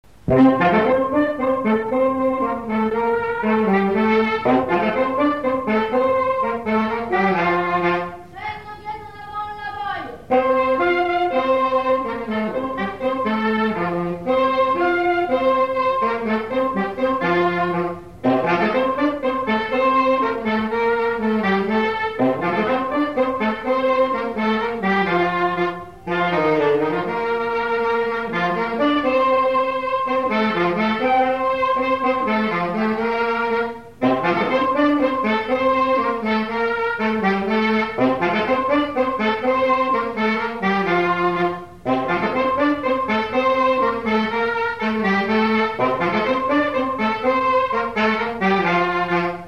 Nalliers
danse : quadrille : chaîne anglaise
Pièce musicale inédite